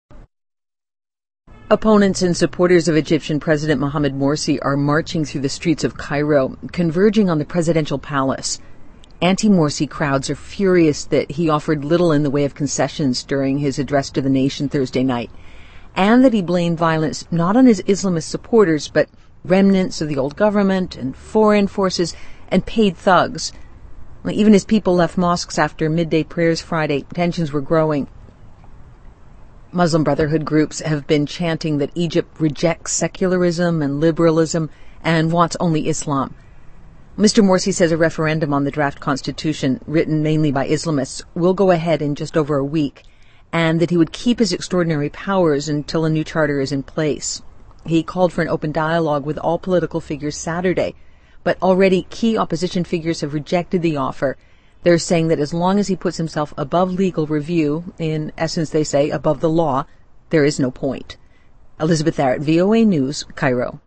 reports from the scene in Cairo